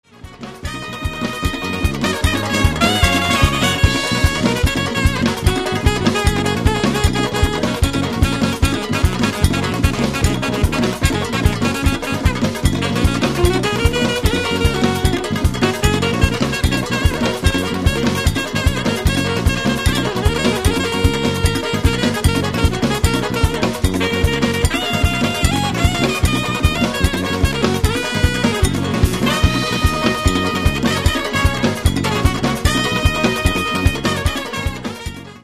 sax solo